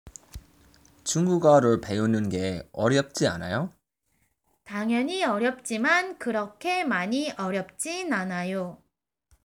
Based on the conversation, is learning Chinese hard?